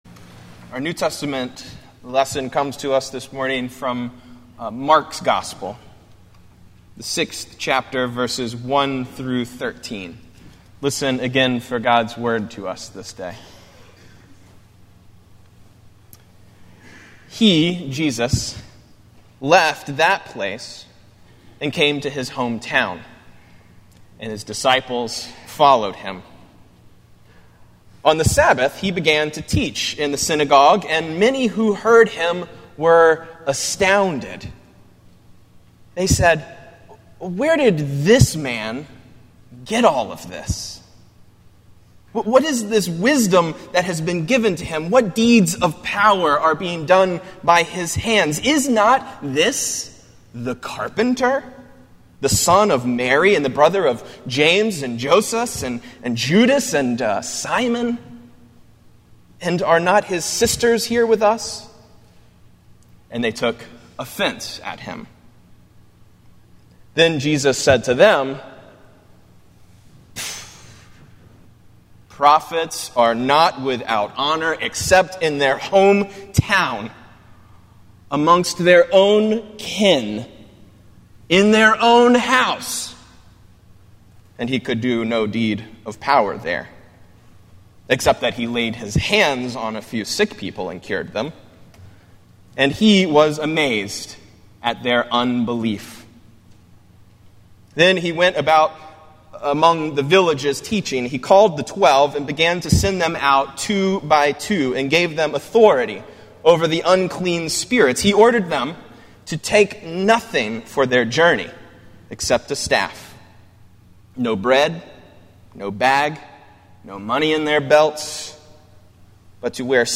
Morningside Presbyterian Church - Atlanta, GA: Sermons: #1 Not in My House